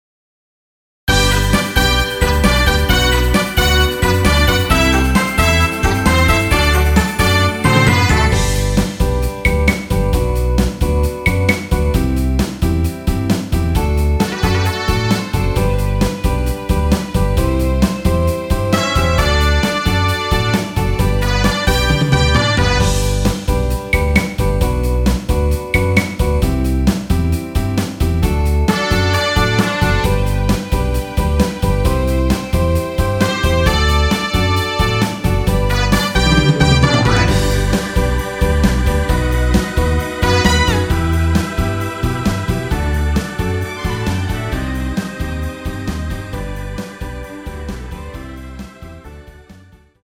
원키에서(+2)올린 MR입니다.
앞부분30초, 뒷부분30초씩 편집해서 올려 드리고 있습니다.
중간에 음이 끈어지고 다시 나오는 이유는
곡명 옆 (-1)은 반음 내림, (+1)은 반음 올림 입니다.